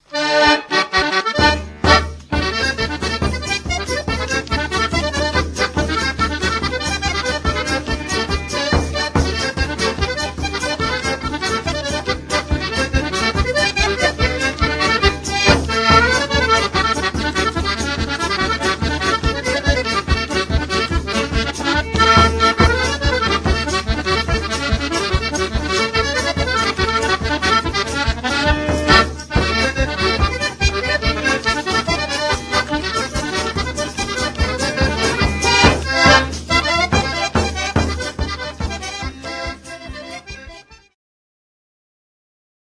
Polka (okolice Gowarczowa, 1985)
harmonia przerobiona z akordeonu "Marinucci"
bębenek